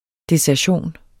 Udtale [ desæɐ̯ˈɕoˀn ]